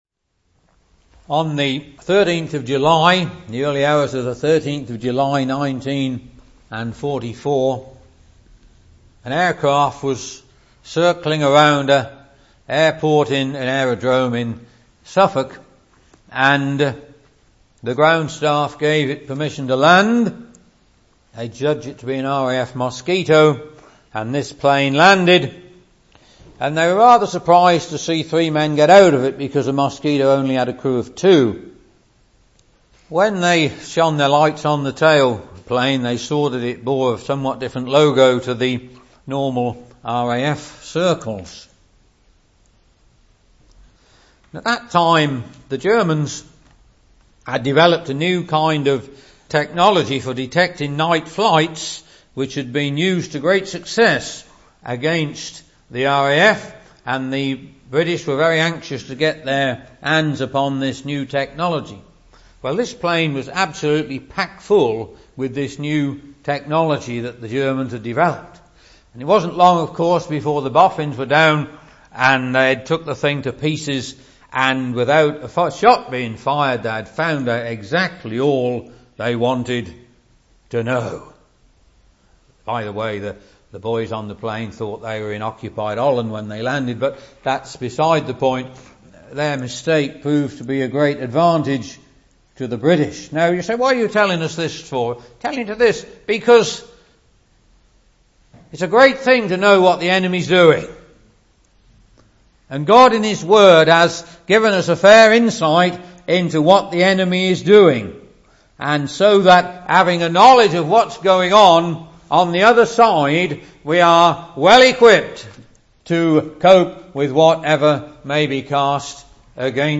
(Message preached 28th July 2016)